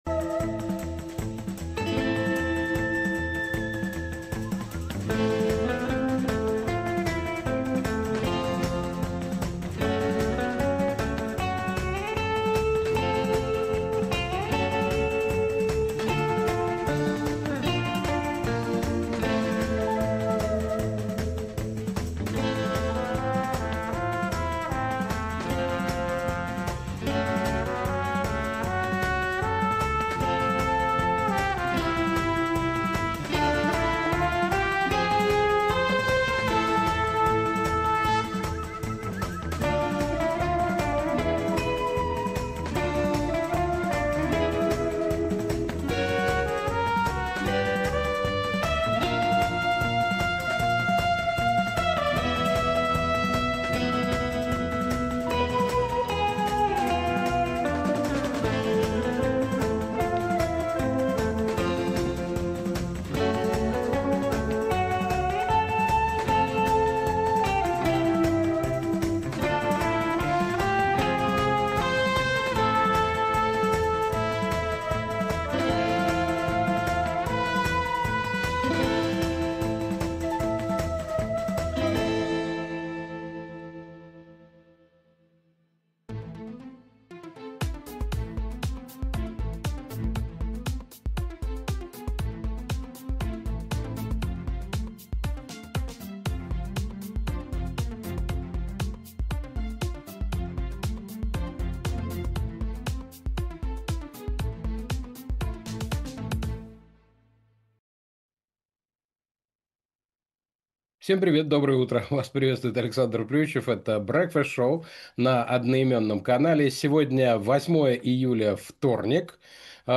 Александр Плющев обсудит с экспертами в прямом эфире The Breakfast Show все главные новости.